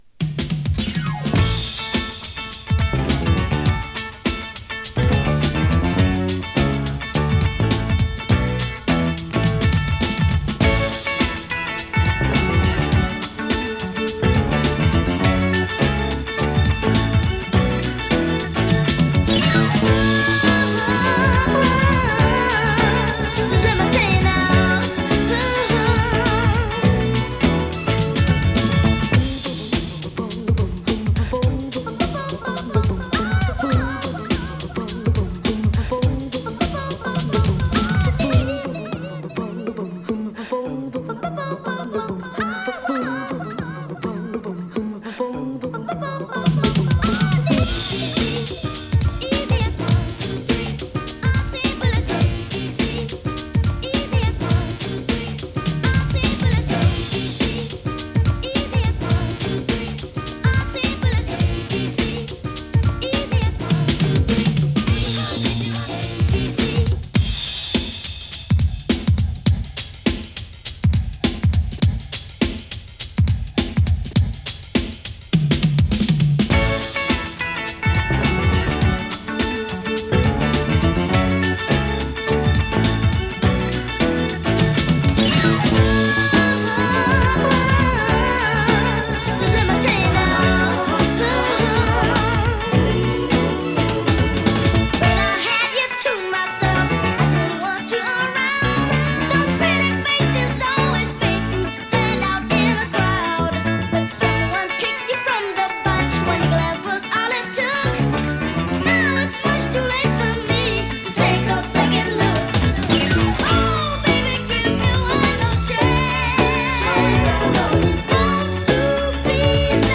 [3version 12inch]＊12inch Remixの最後に極軽いパチ・ノイズx1。